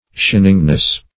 Shiningness \Shin"ing*ness\, n.